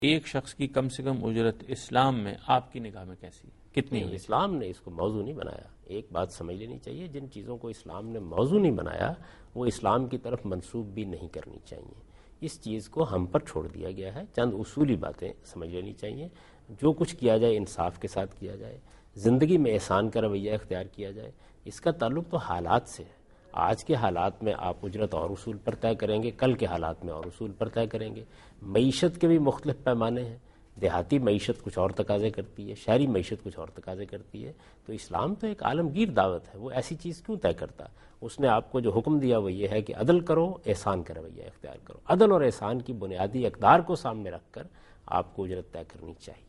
Category: TV Programs / Dunya News / Deen-o-Daanish /
Javed Ahmad Ghamidi Answer the Question on Minimum Wage in Islam In Program Deen o Danish